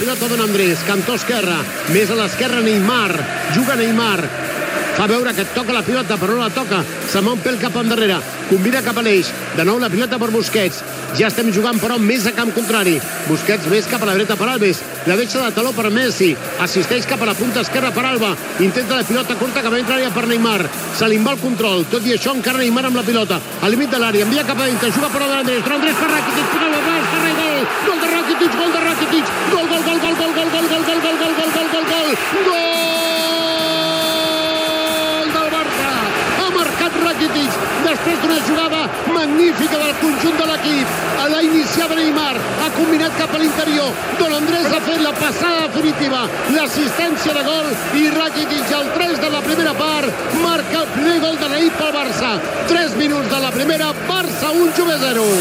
Transmissió de la final de la Copa d'Europa de futbol masculí, des de l'Olympiastadion de Berlín, entre el Futbol Club Barcelona i la Juventus de Milàs.
Narració del gol d'Ivan Rakitić (1-0)
Esportiu